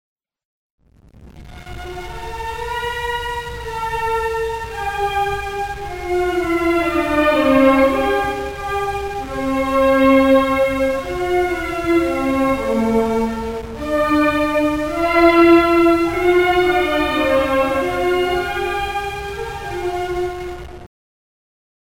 There are just two traditional music sources for the Fantasy: the wedding song From behind the mountains, the high mountains
wedding.mp3